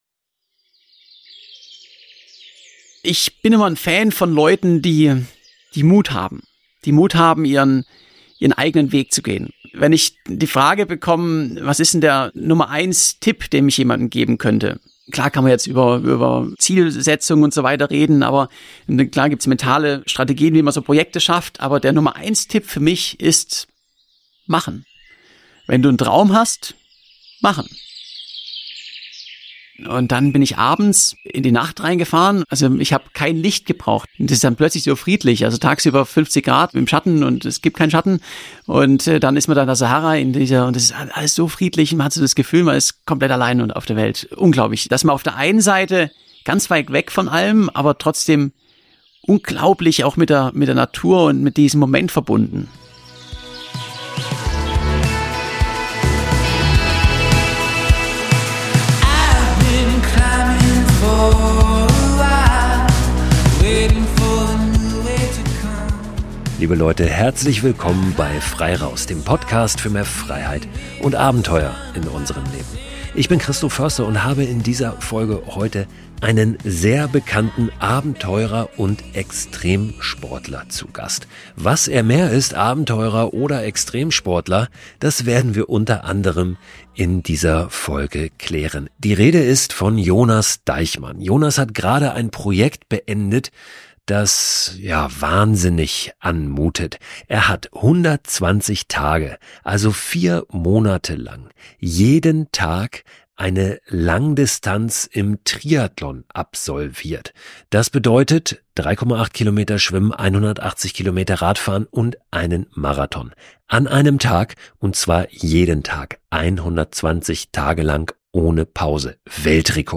Aber genau deshalb habe ich ihn gefragt, ob er Lust auf ein Gespräch hat. Ich wollte wissen, was hinter den Rekorden steckt.